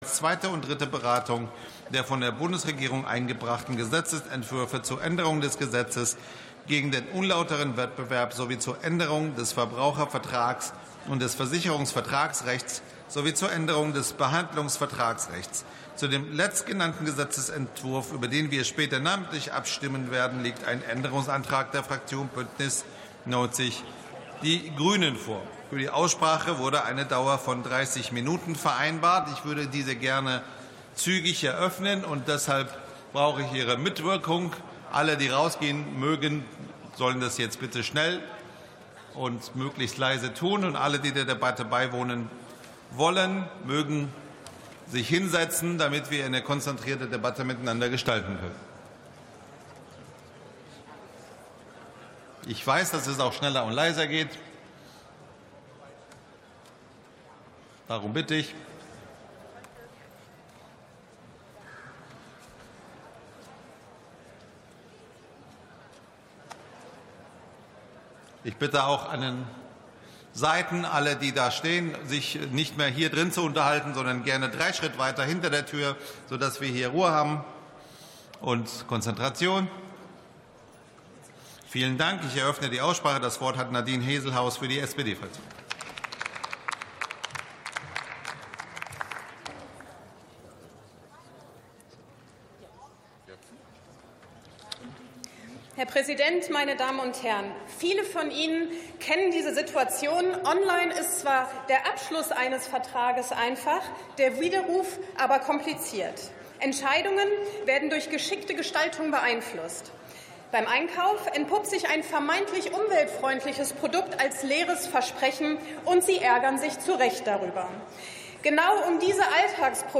Plenarsitzungen - Audio Podcasts